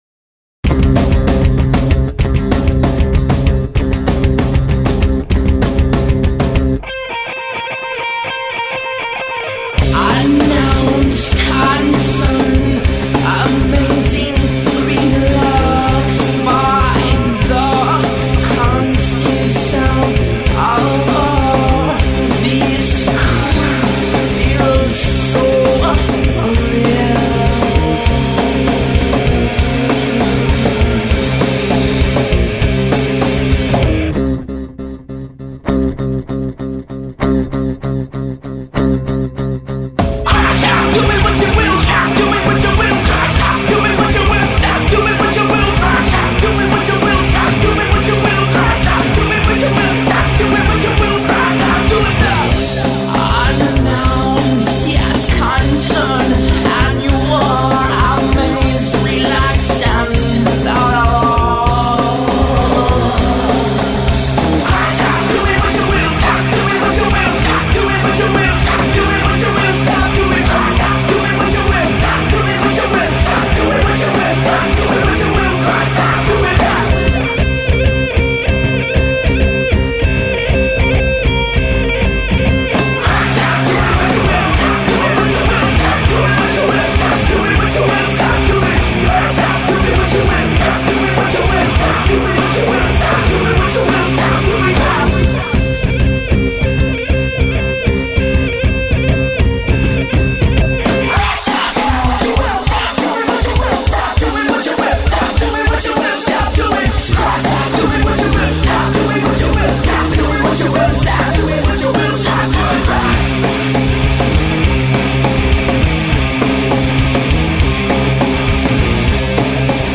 Recorded in Riverside, CA and Redlands, CA in 2003.